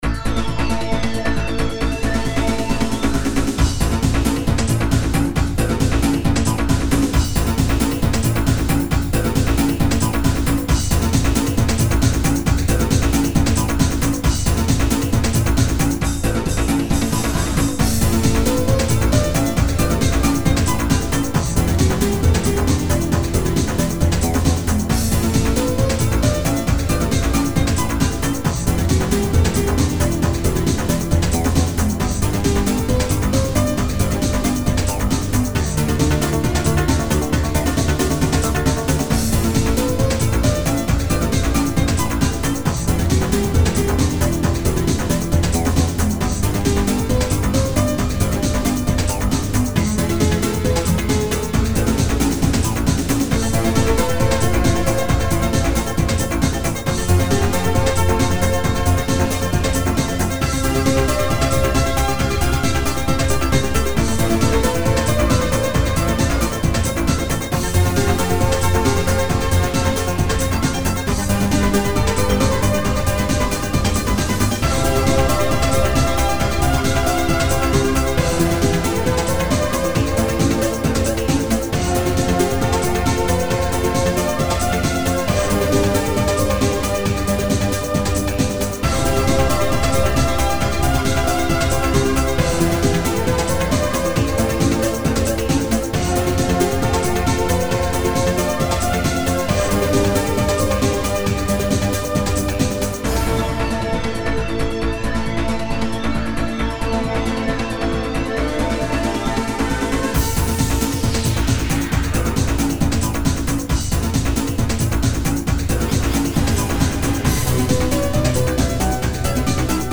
Synthpop